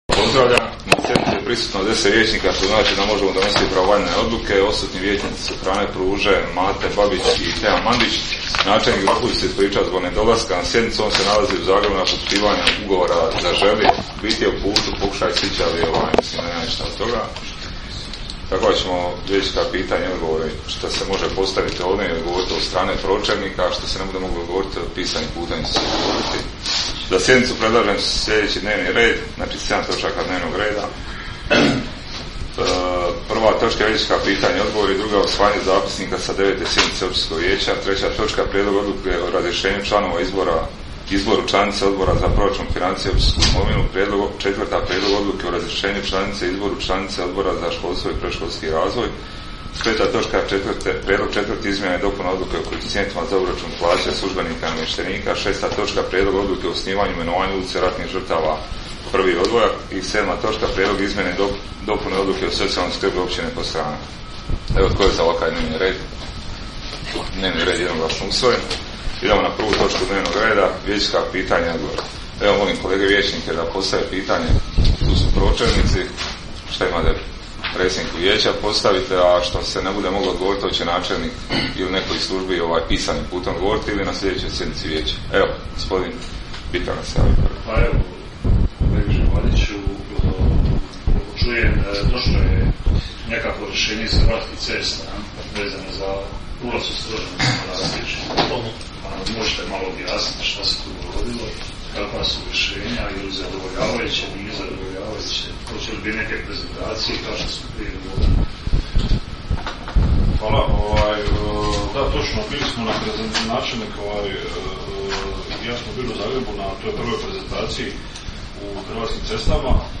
Sjednica će se održati dana 28. srpnja (četvrtak) 2022. godine u 19,00 sati u vijećnici Općine Podstrana.
10.-sjednica-vijeca-audio.mp3